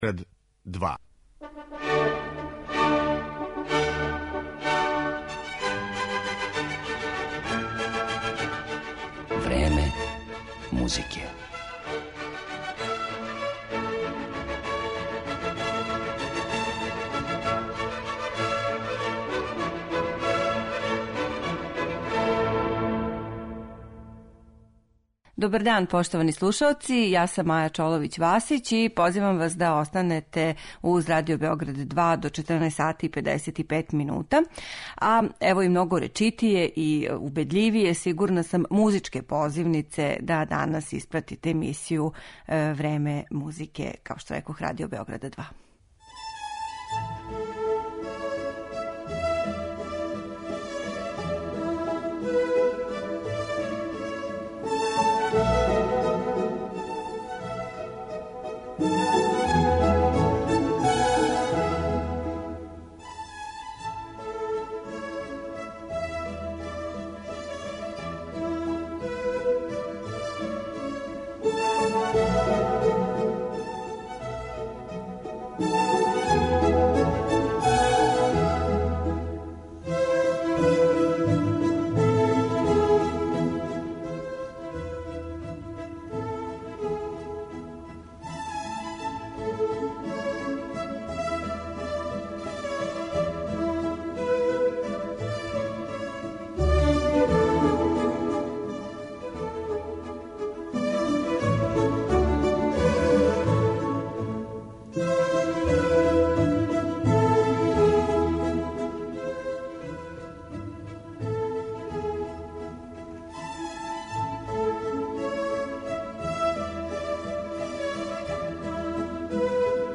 Фрагменти из Бокеринијевих познатих дела